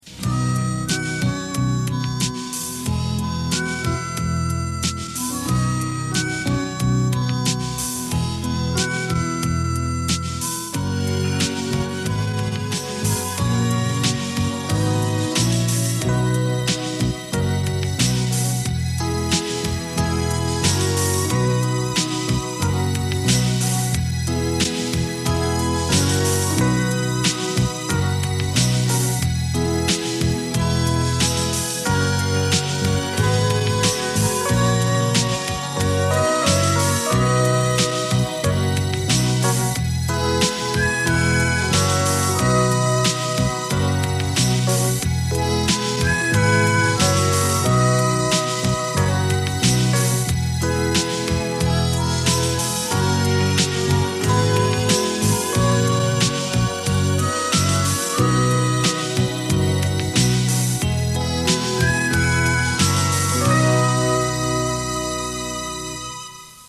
Sintonia de l'emissora